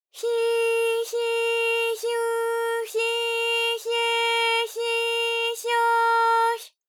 ALYS-DB-001-JPN - First Japanese UTAU vocal library of ALYS.
hyi_hyi_hyu_hyi_hye_hyi_hyo_hy.wav